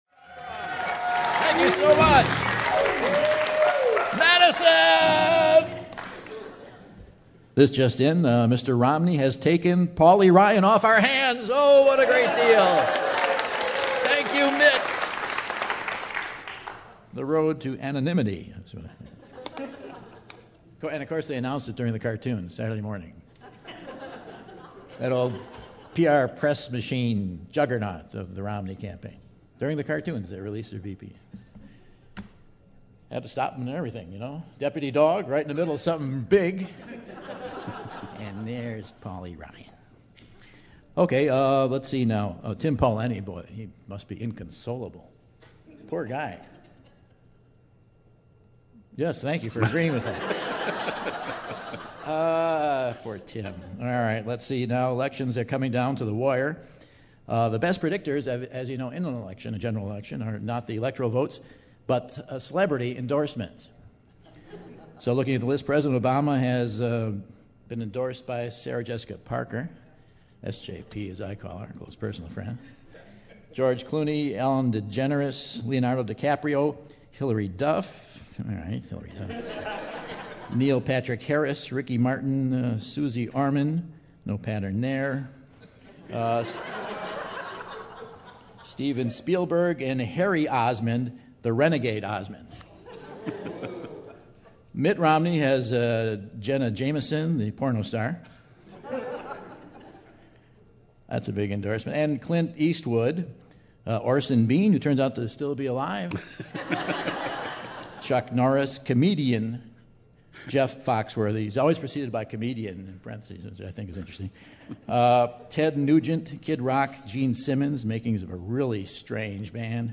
August 11, 2012 - Madison, WI - Historic Music Hall | Whad'ya Know?